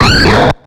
Cri de Carmache dans Pokémon X et Y.